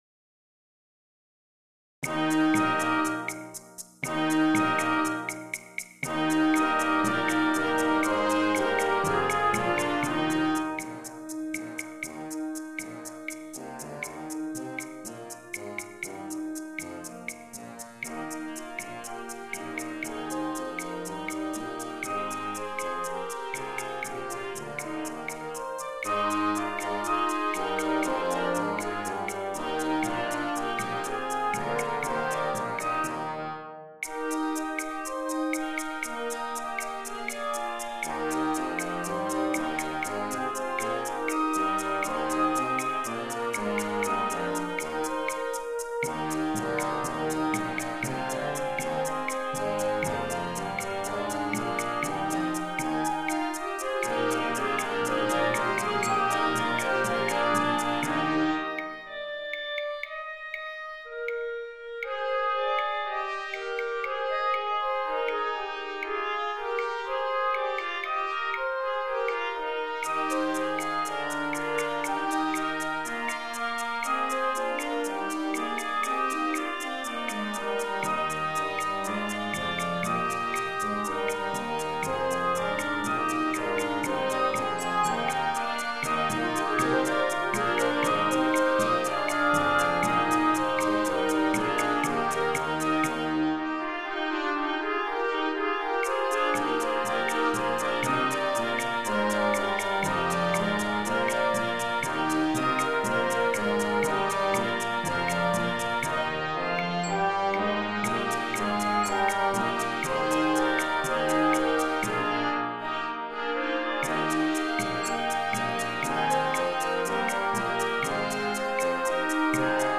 Concert Band Grade 2